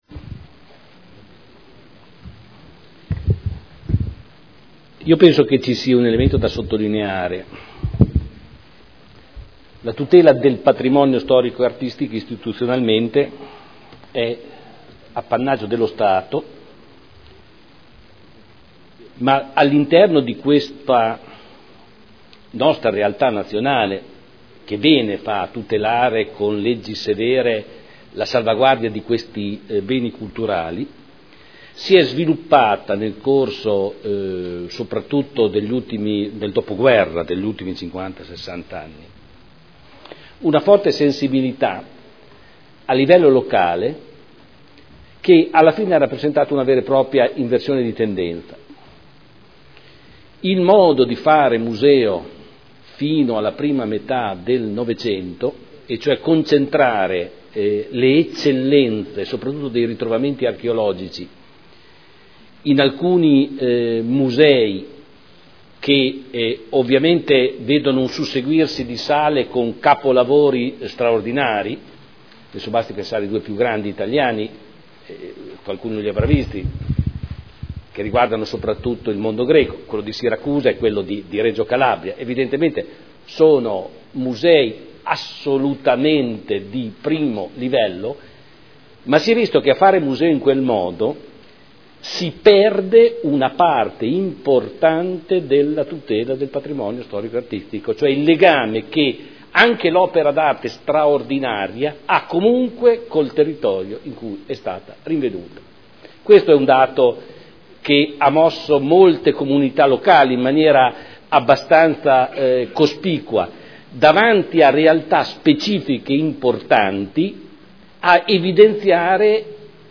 Giorgio Pighi — Sito Audio Consiglio Comunale
Seduta del 08/04/2013 Dibattito.